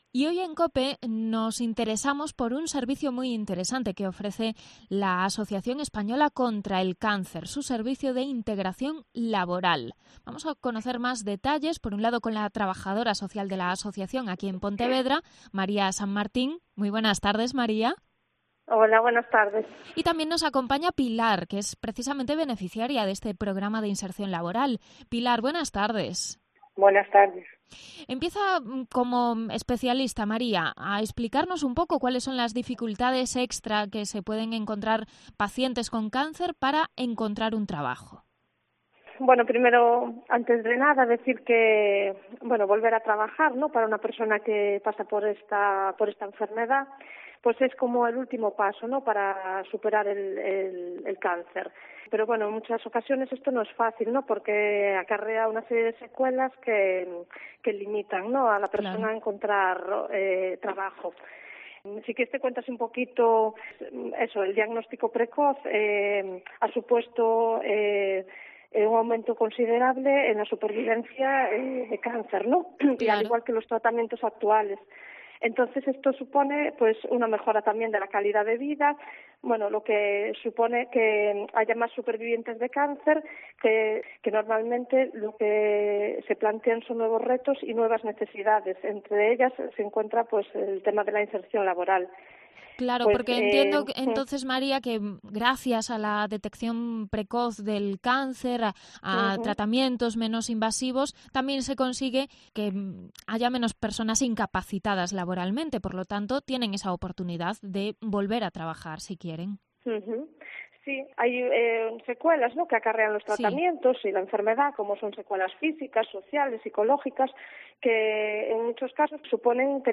Entrevista sobre volver a encontrar trabajo tras superar el cáncer